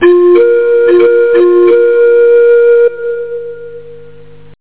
PSION CD 2 home *** CD-ROM | disk | FTP | other *** search / PSION CD 2 / PsionCDVol2.iso / Wavs / FLUTE ( .mp3 ) < prev next > Psion Voice | 1998-08-27 | 37KB | 1 channel | 8,000 sample rate | 4 seconds
FLUTE.mp3